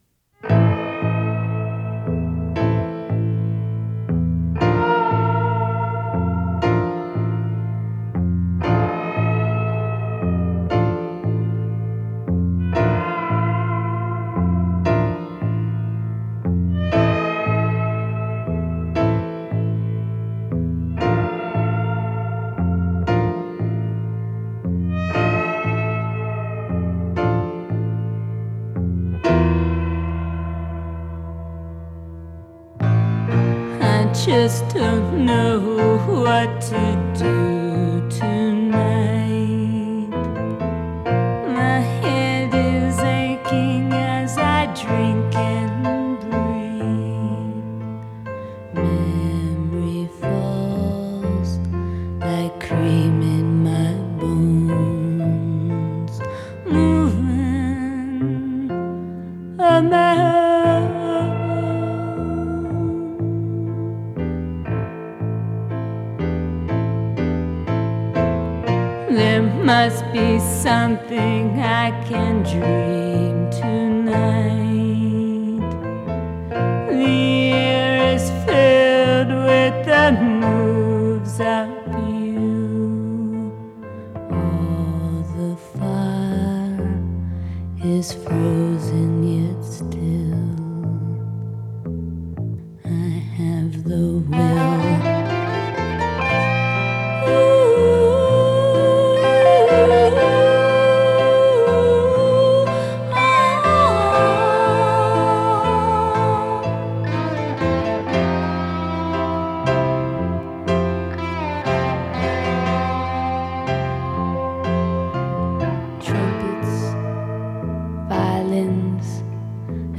Жанр: Рroto-Punk, Rock